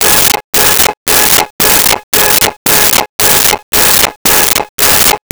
Car Alarm
Car Alarm.wav